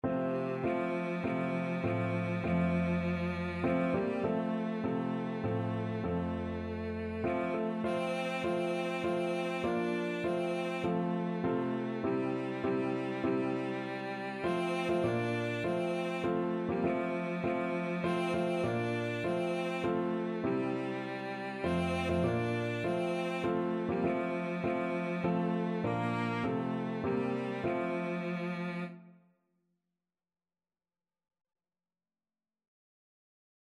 3/4 (View more 3/4 Music)
Classical (View more Classical Cello Music)